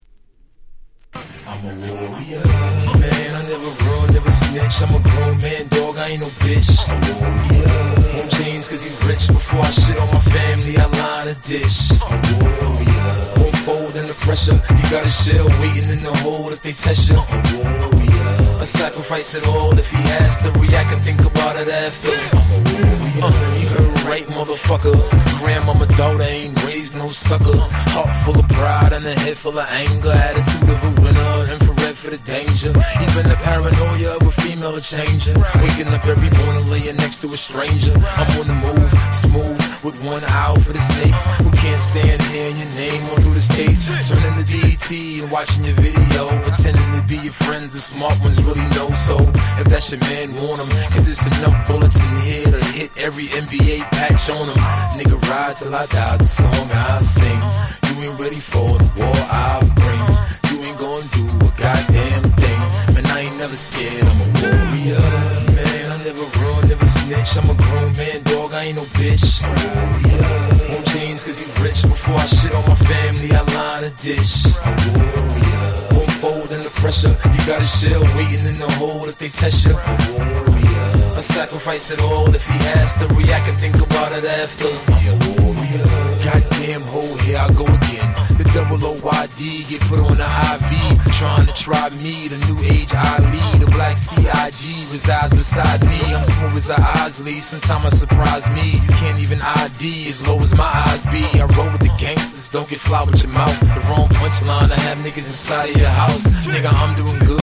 04' Smash Hit Hip Hop !!